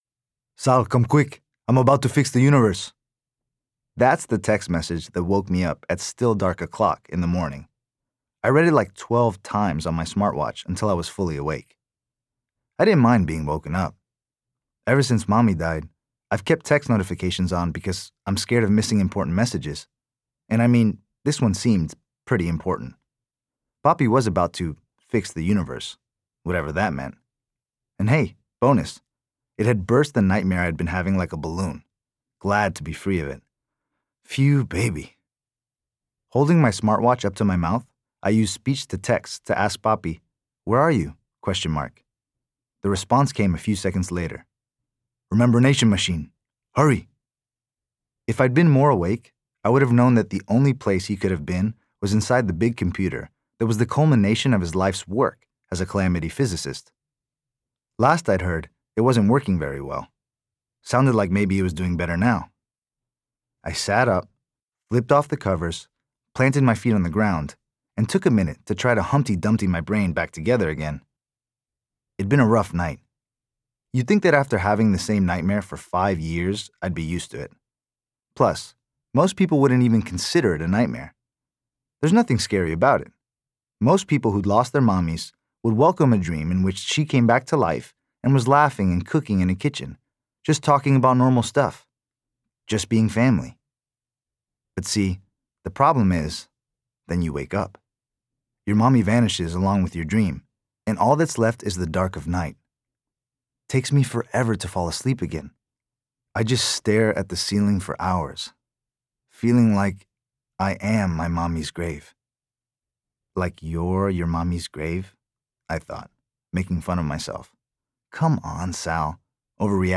Listening to Audiobook
In each case, strong, empathetic performances from the narrators make the characters and stories relatable to a wide range of listeners.